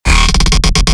bass house one shots
Vortex_Basses_D#_14